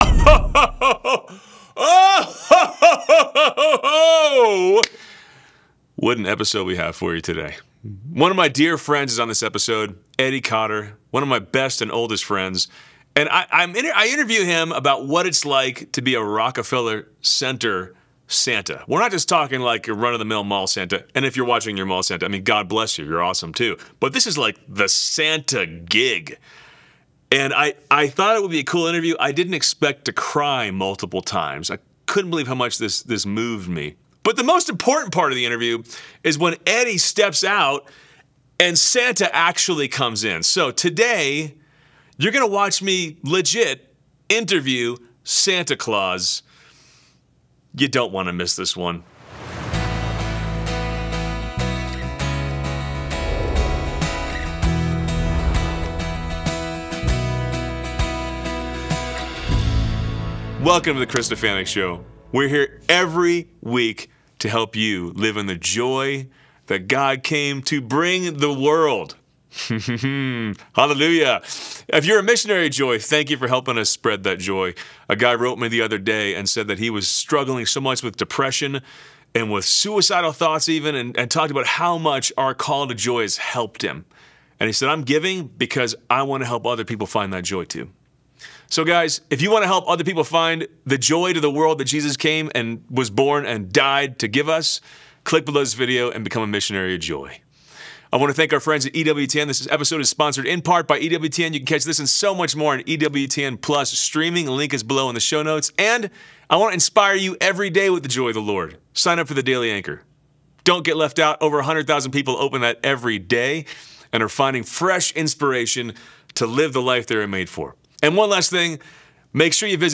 Exclusive Interview with Santa Claus | What It's Like to Be Santa